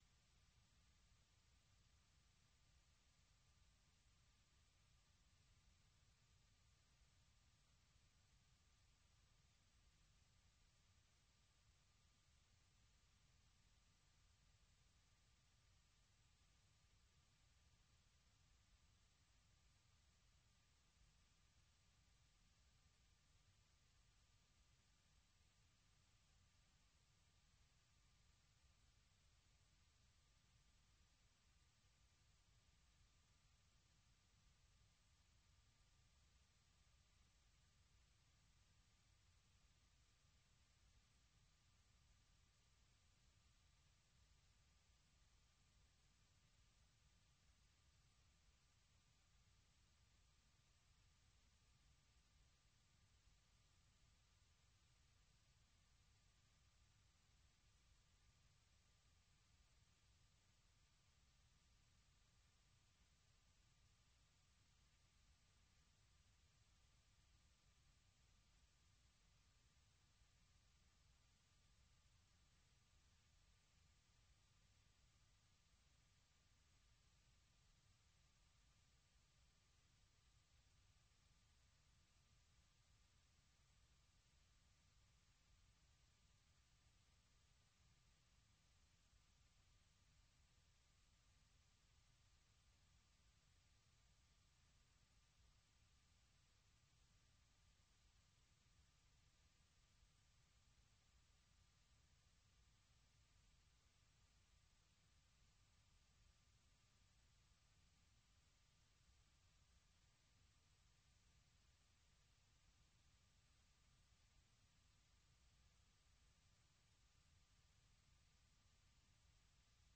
propose notamment d'écouter de la musique africaine
des reportages et interviews sur des événements et spectacles africains aux USA ou en Afrique.